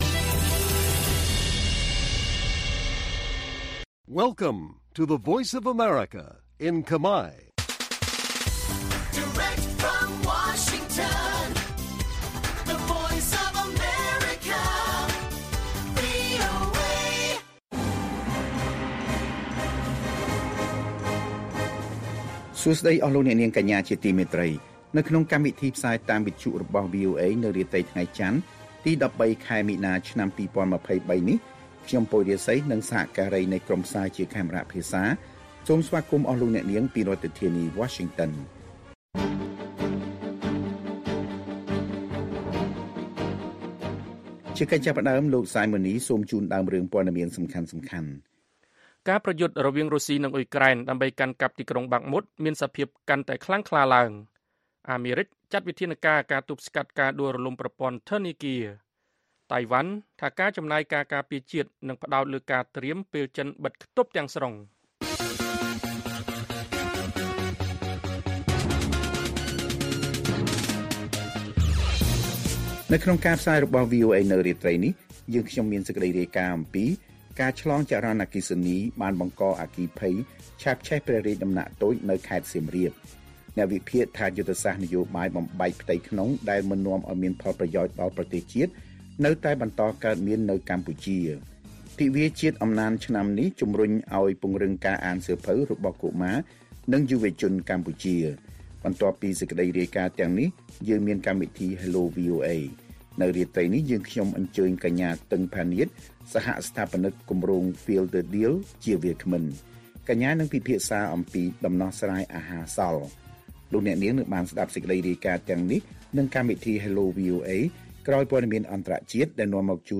ព័ត៌មានពេលរាត្រី ១៣ មីនា៖ ការឆ្លងចរន្តអគ្គិសនីបានបង្កឱ្យមានអគ្គិភ័យឆាបឆេះព្រះរាជដំណាក់តូចនៅខេត្តសៀមរាប